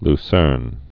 (l-sûrn, lü-sĕrn)